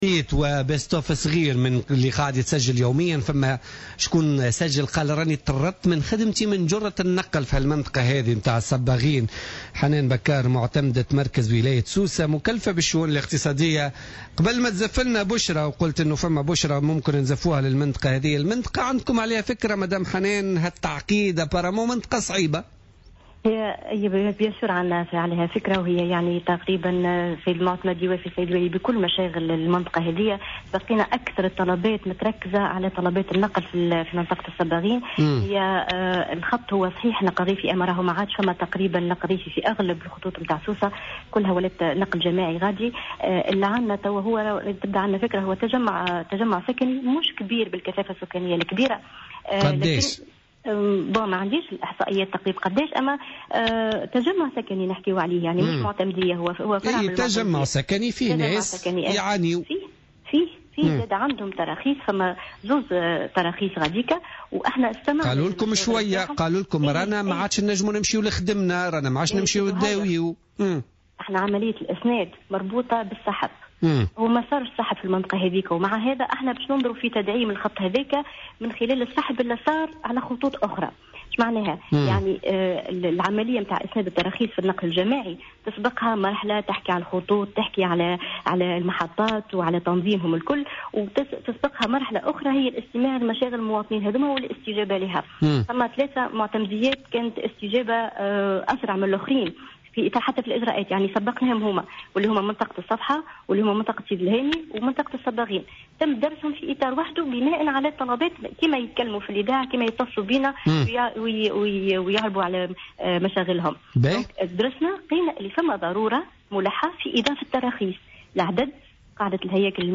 أكدت حنان بكار معتمد مركز ولاية سوسة المكلفة بالشؤون الإقتصادية في مداخلة لها في بوليتيكا اليوم الجمعة 26 فيفري 2016 أن أكثر الطلبات الواردة على المعتمدية من منطقة الصباغين هي طلبات تتعلق بمشكل النقل وفق قولها.